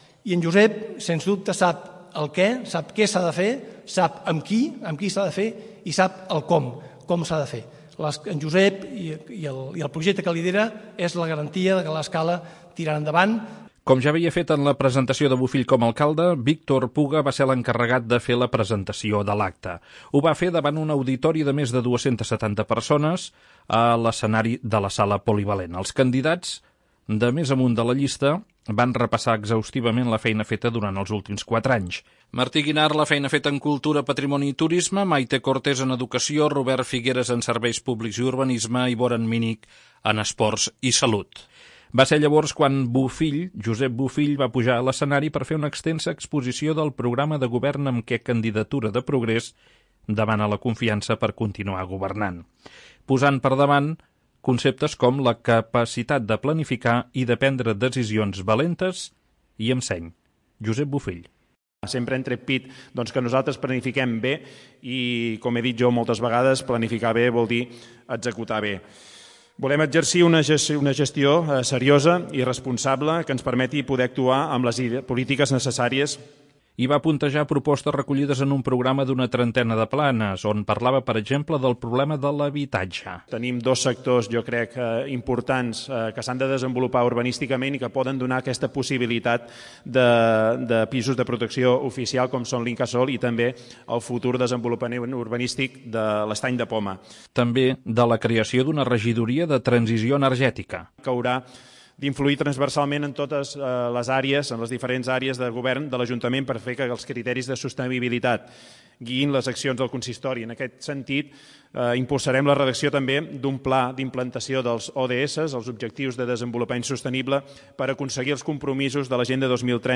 CP presenta llista i programa en una Sala Polivalent plena